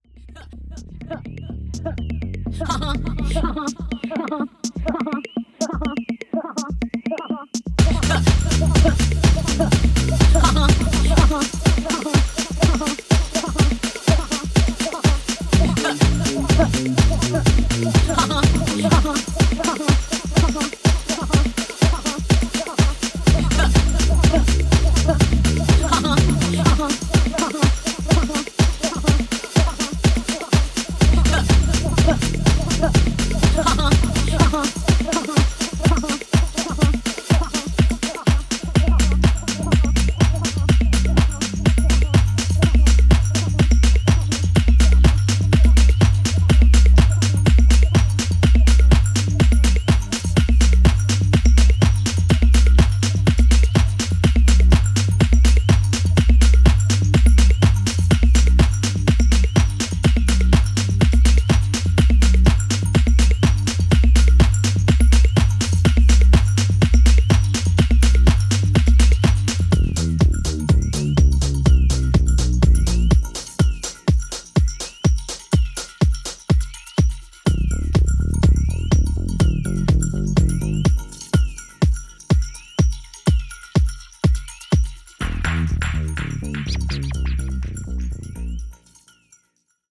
DUB ELECTRONIQUE
ジャンル(スタイル) HOUSE / SOULFUL HOUSE / DISCO HOUSE